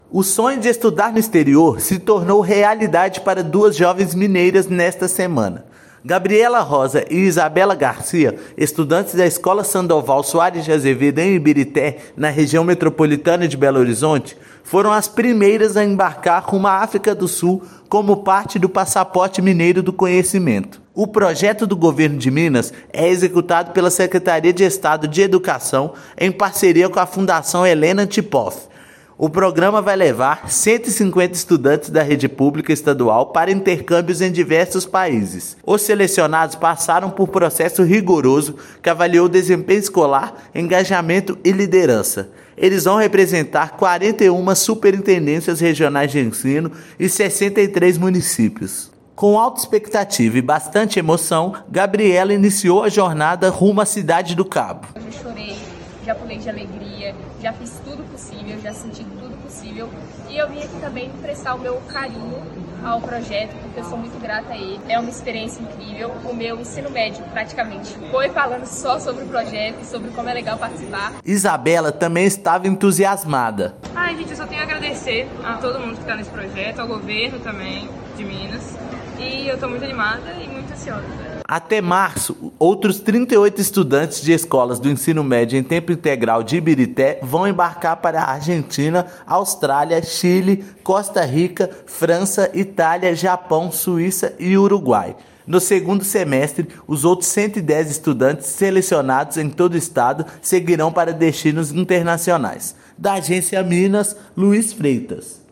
Agência Minas Gerais | [RÁDIO] Primeiras estudantes selecionadas no Passaporte Mineiro do Conhecimento embarcam para intercâmbio na África do Sul
Projeto levará 150 jovens do Ensino Médio em Tempo para estudar em diversos países em 2025; governador acompanhou embarque em Confins. Ouça matéria de rádio.